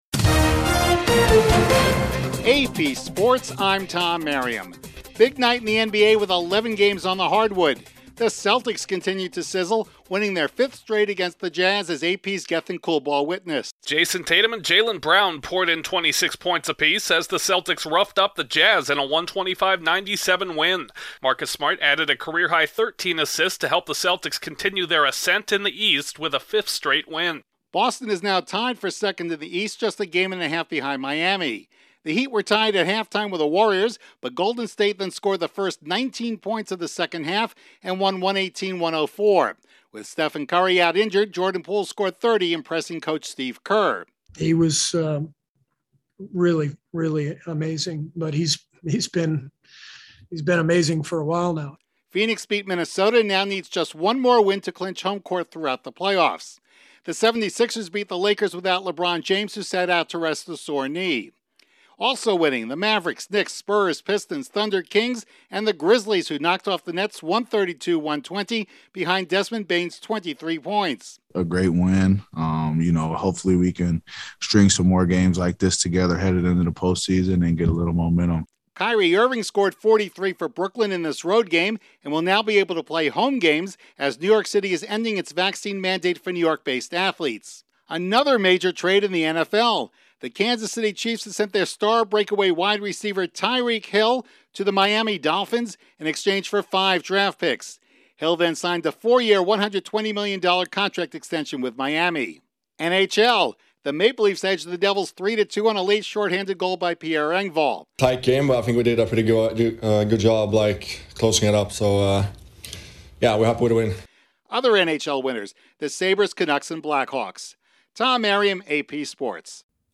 The Celtics keep climbing the NBA's Eastern Conference standings, the Warriors rally in the second half, the Grizzlies knock off the Nets, New York City will drop vaccine mandates for athletes and the Dolphins get a deep threat. Correspondent